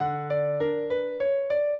piano
minuet4-12.wav